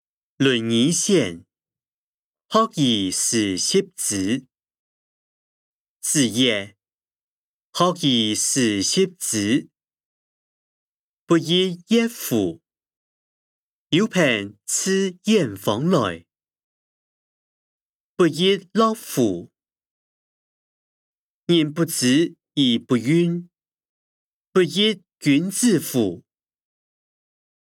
經學、論孟-論語選．學而時習之音檔(四縣腔)